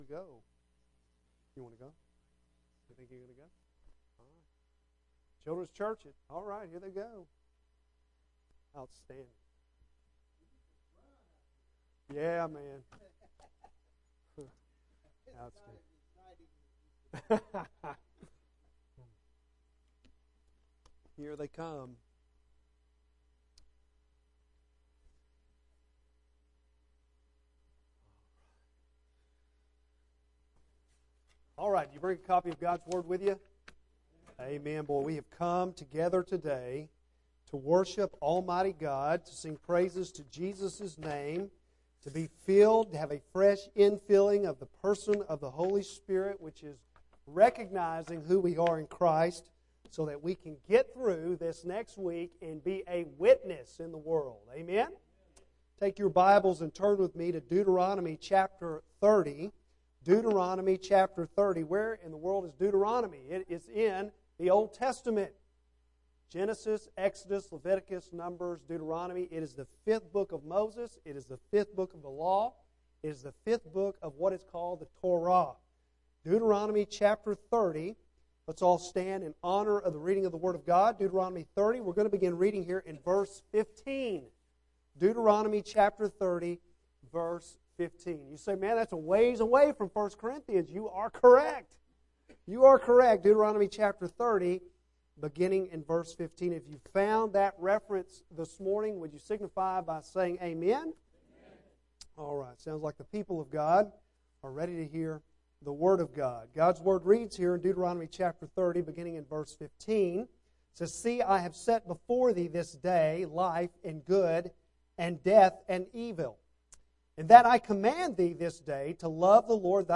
Bible Text: Deuteronomy 30:15-20 | Preacher